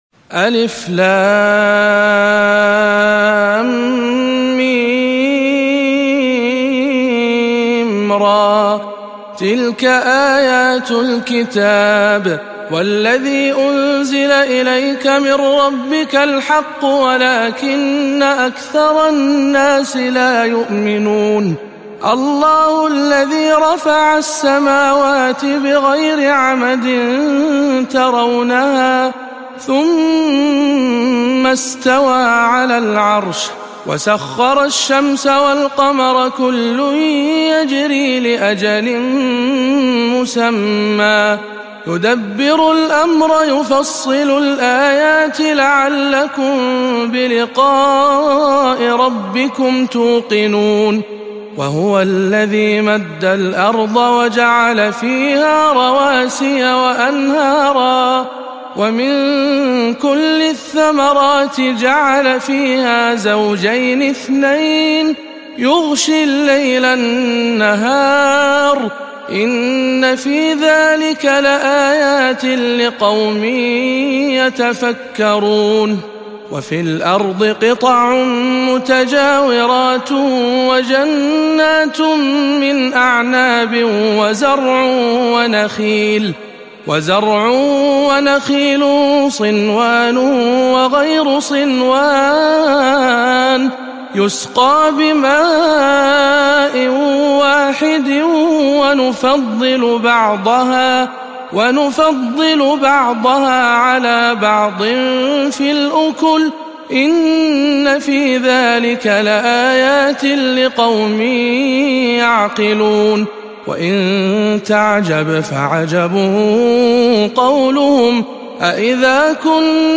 سورة الرعد بجامع الأمير محمد بن سعود ببلجرشي